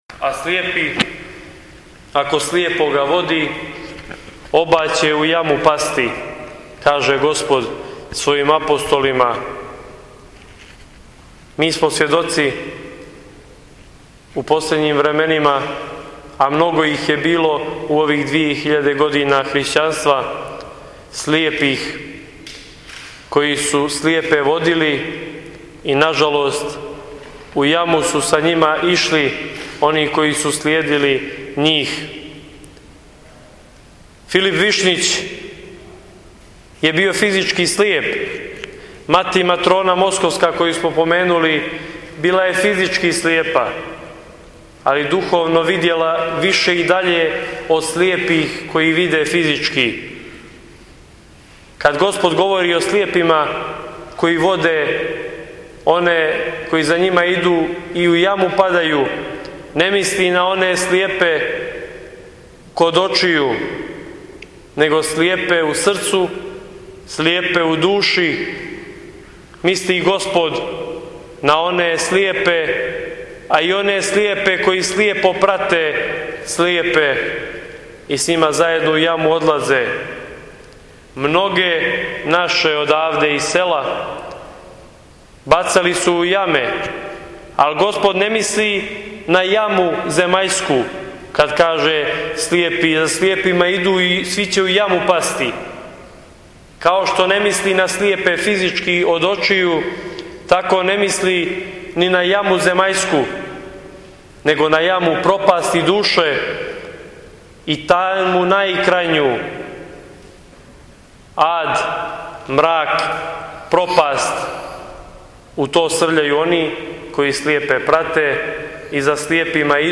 Завјет села Шишићи-Огњена Марија-2014.